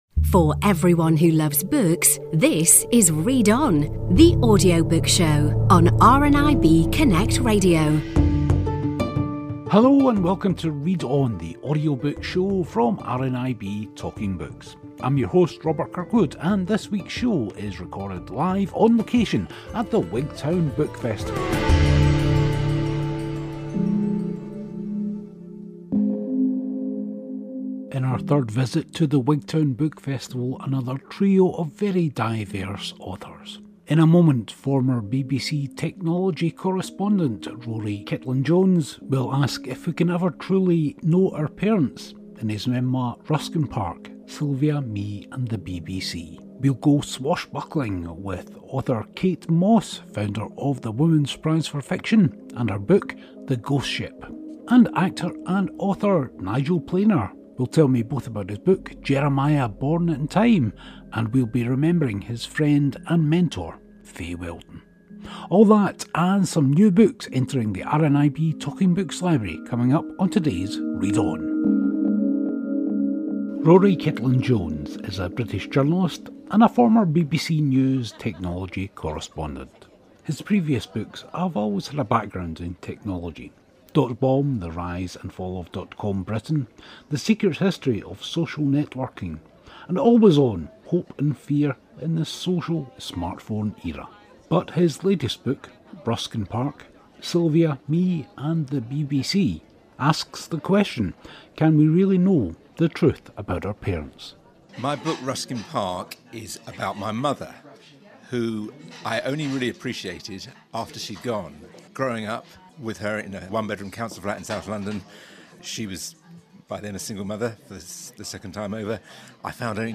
Three more authors interviewed at the Wigtown Book Festival in this week's show.